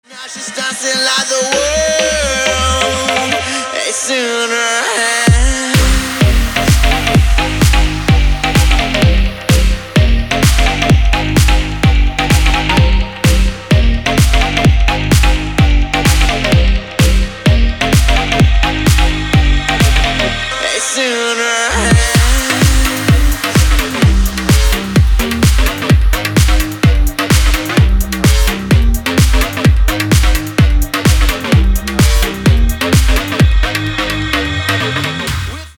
Рингтоны динамичные
Клубные